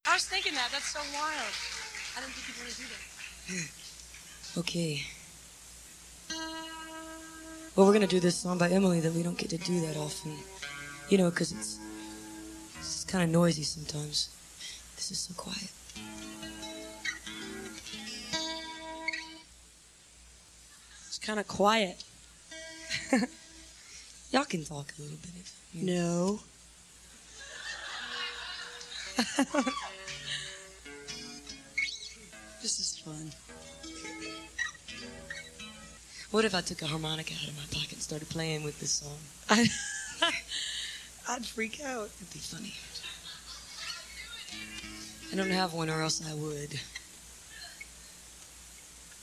(acoustic duo show)
33. talk (0:49)